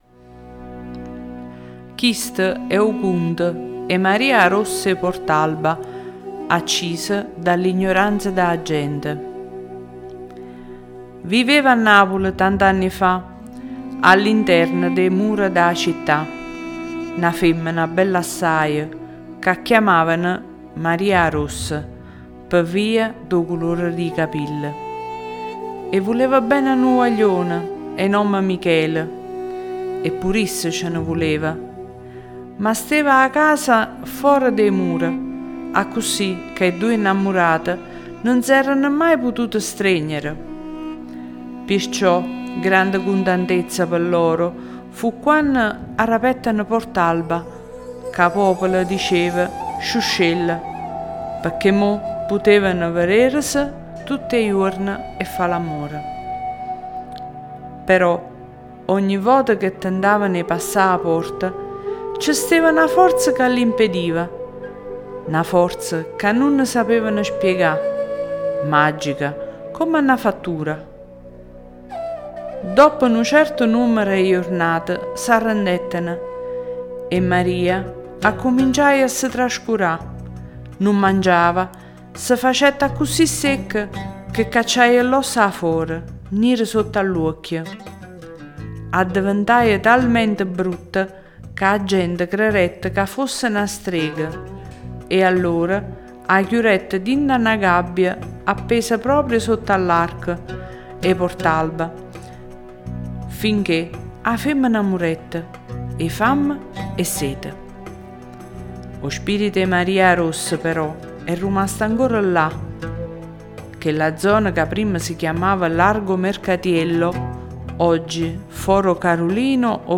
Le favole della buonanotte
Leggi e ascolta la storia Maria ‘a rossa in dialetto napoletano
Questa sera usciamo fuori dai soliti schemi e narriamo a due voci una storia che rende giustizia al nostro amato dialetto.